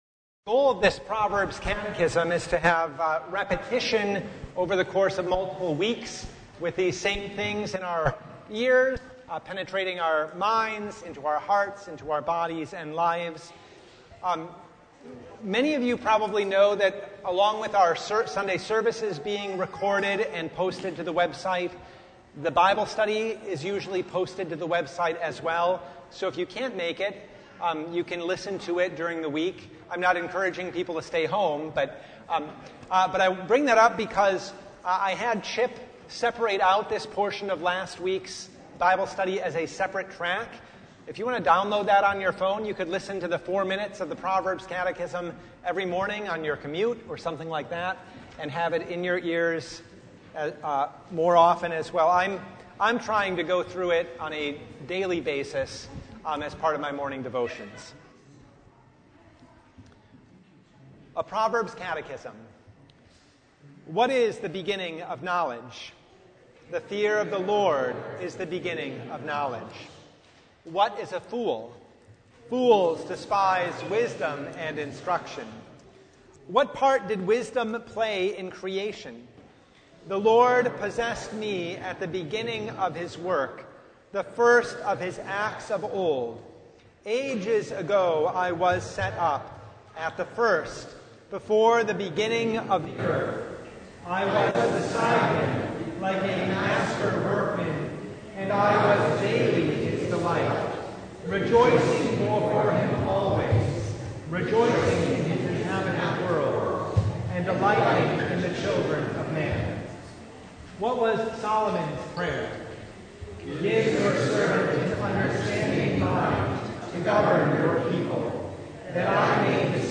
Proverbs 1:8–33 Service Type: Bible Hour Sinners entice
Topics: Bible Study « The Sixth Sunday in Apostles’ Tide (2024) More Bread?